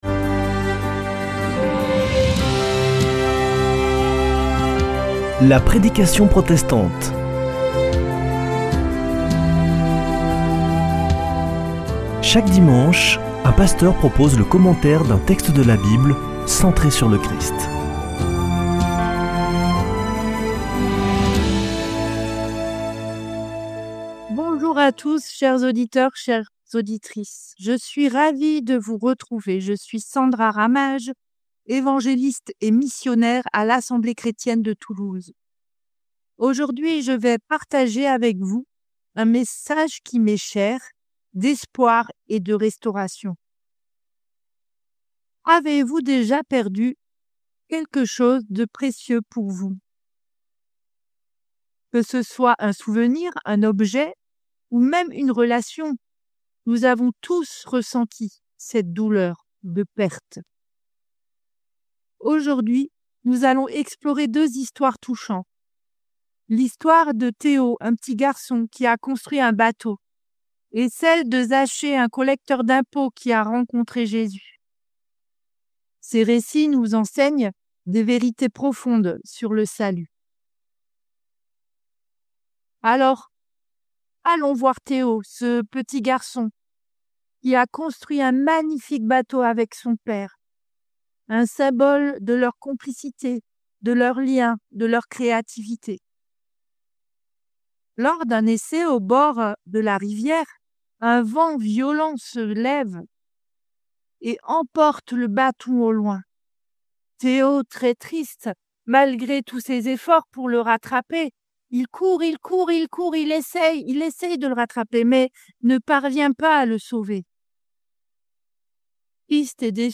Accueil \ Emissions \ Foi \ Formation \ La prédication protestante \ Un message d’Espoir : restauration et transformation spirituelle.